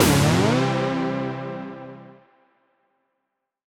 Index of /musicradar/future-rave-samples/Poly Chord Hits/Ramp Up